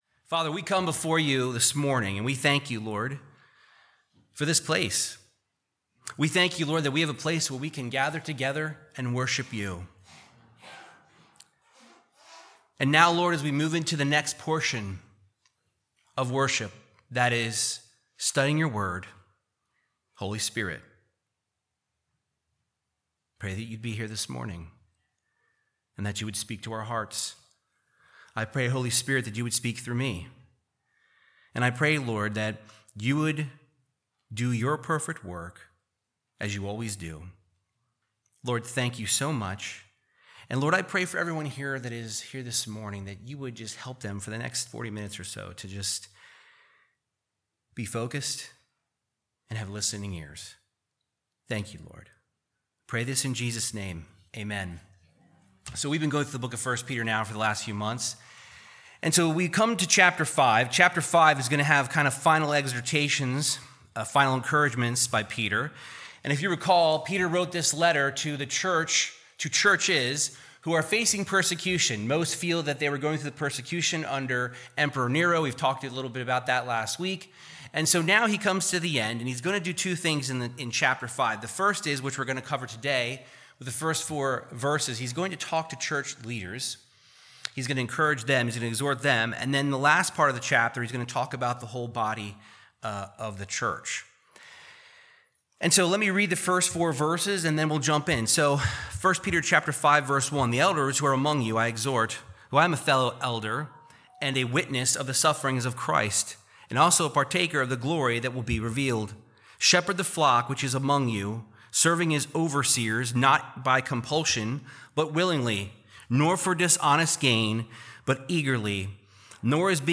Verse by verse Bible teaching of 1 Peter 5:1-4